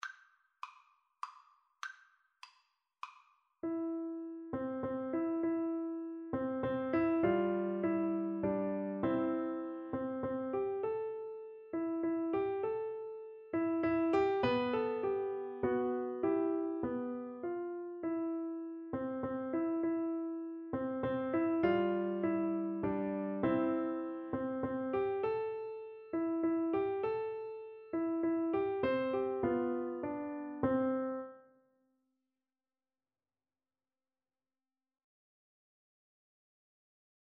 3/4 (View more 3/4 Music)
Moderato
Piano Duet  (View more Beginners Piano Duet Music)